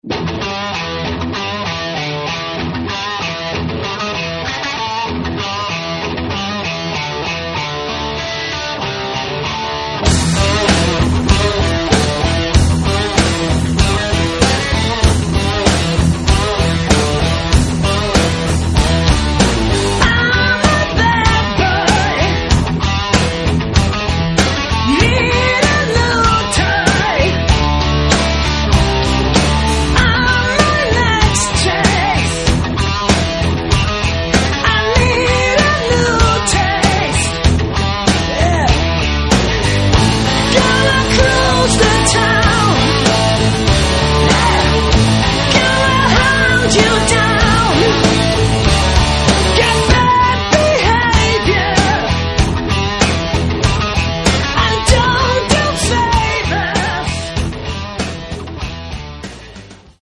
Рок
Этот альбом получился более мощным и страстным.